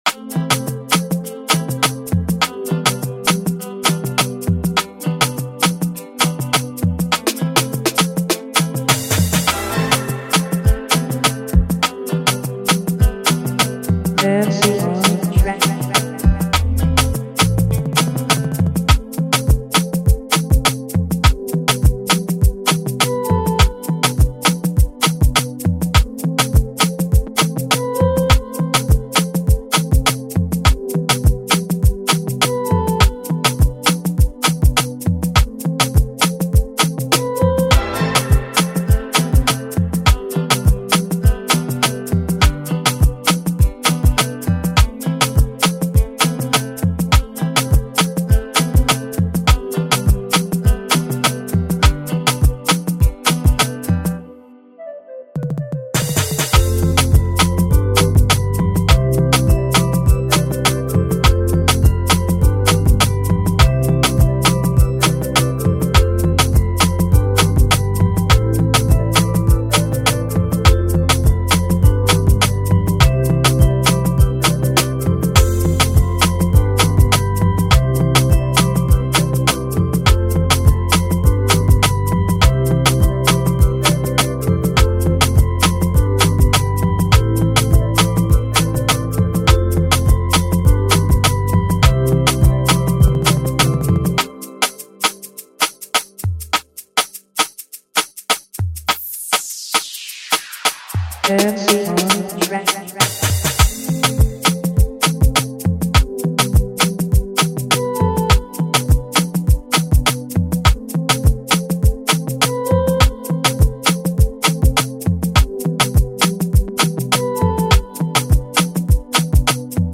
This is the instrumental remake
hip hop